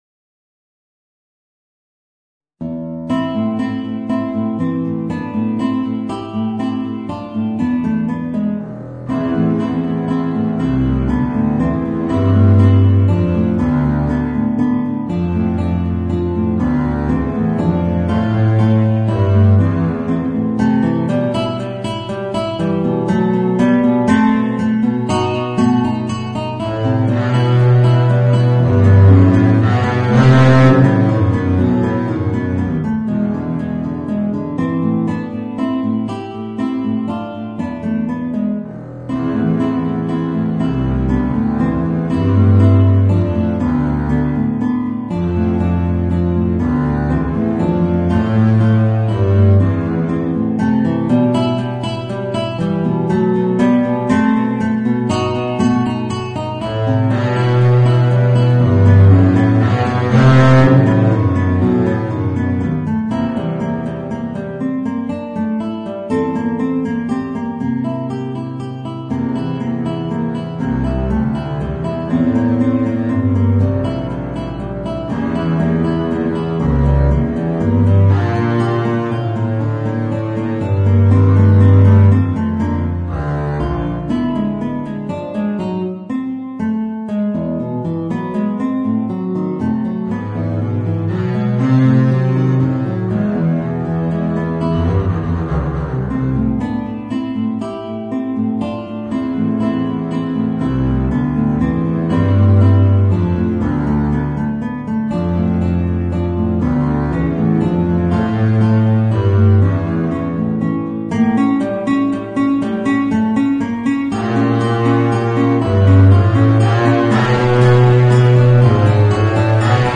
Voicing: Contrabass and Guitar